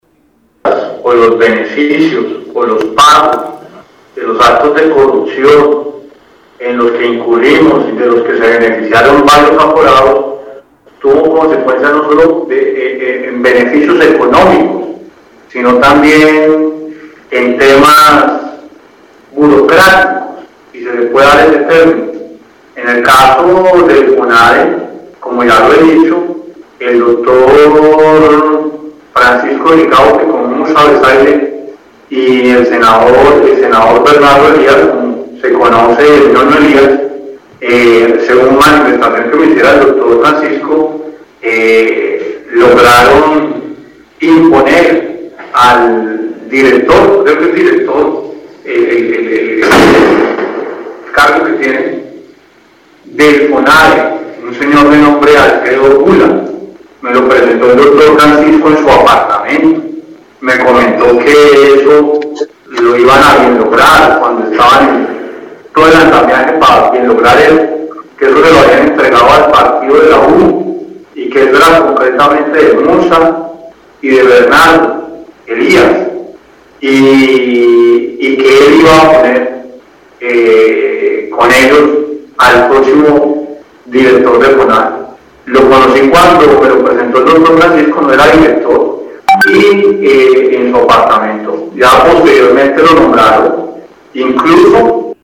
Gustavo Moreno, exfiscal anticorrupción, entregó su testimonio durante el juicio que se adelanta contra el magistrado Francisco Ricaurte por el llamado Cartel de la Toga.
Moreno Rivera, quien habló por video conferencia, dijo que Fonade era un fortín político de Musa Besaile y Bernardo ‘El Ñoño’ Hernández, y que esa entidad se la habían entregado al partido de la U.